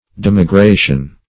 Meaning of demigration. demigration synonyms, pronunciation, spelling and more from Free Dictionary.
Demigration \Dem`i*gra"tion\n.